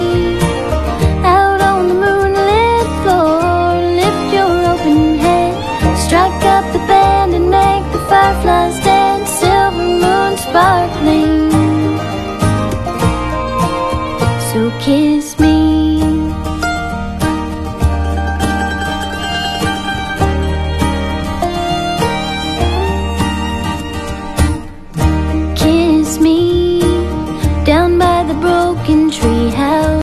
a romantic Mp3 Sound Effect Kiss me - a romantic scene full of emotions and love!